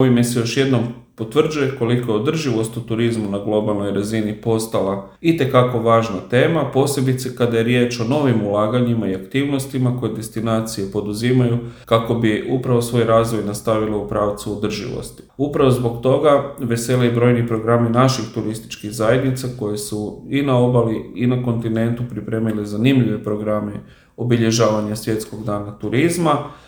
Tim povodom direktor HTZ-a Kristjan Staničić za Media servis je je uputio prigodnu čestitku i pozvao građane da uživaju u brojnim programima turističkih zajednica po povoljnijim cijenama ili besplatno.